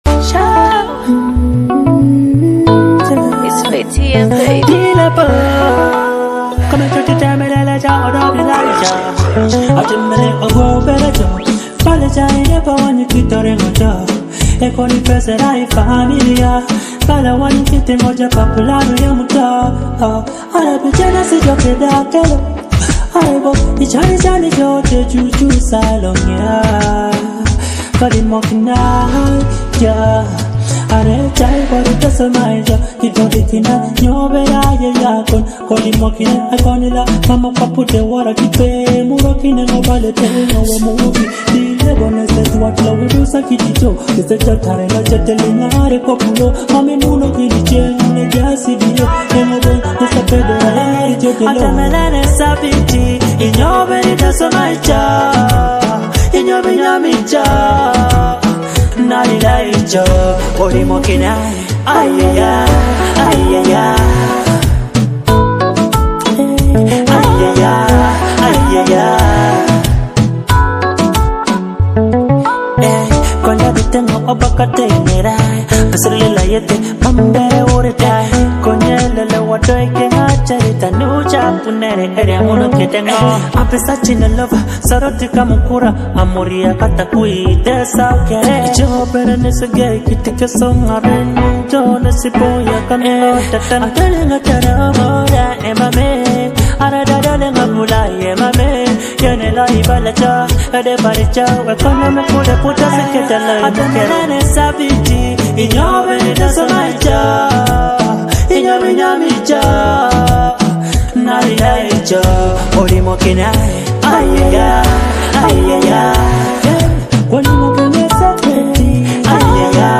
romantic dancehall track